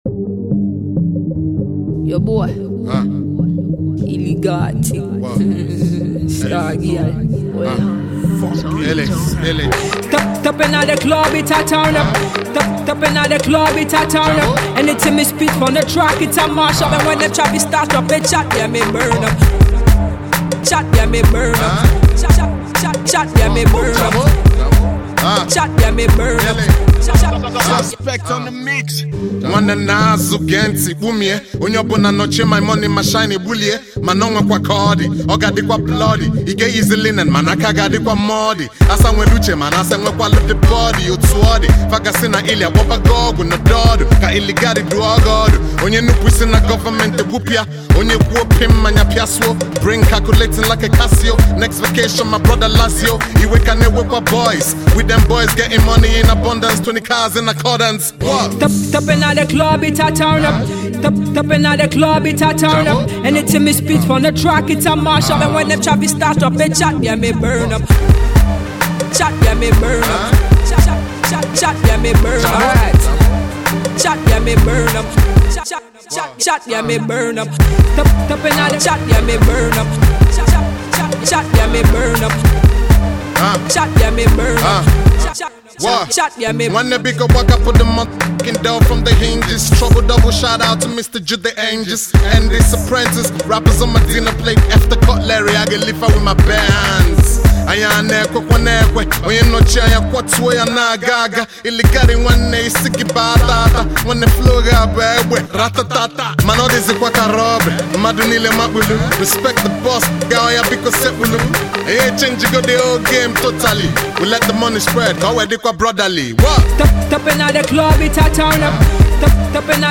a Patois hook
Strong Igbo Bars